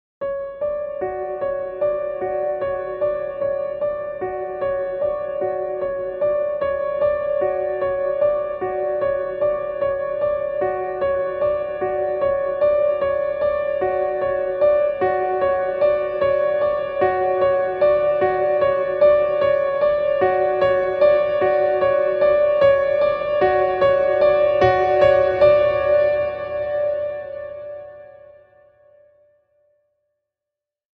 Cinematic Slow Piano Intro With Tense Atmosphere
A slow dramatic piano melody builds tension and emotion.
The tension slowly builds as the melody progresses.
Genres: Sound Logo